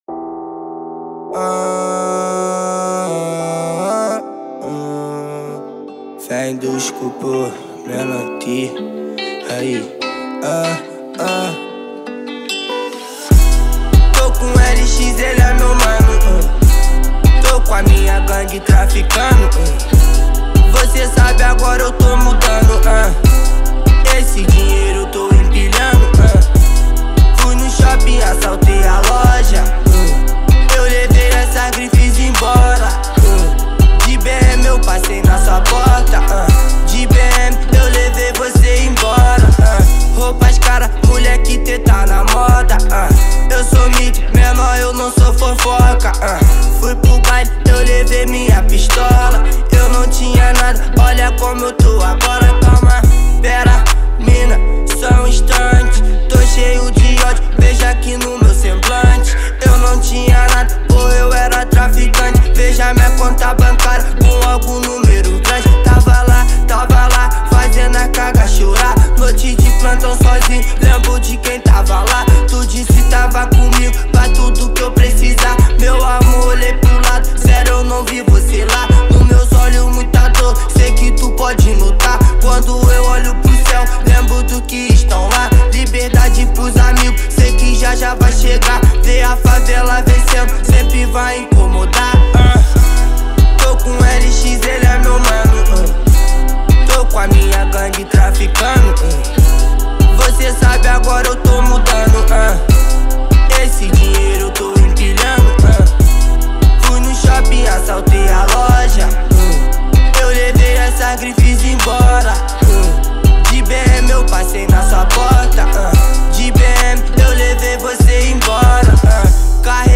2024-07-23 14:33:08 Gênero: Funk Views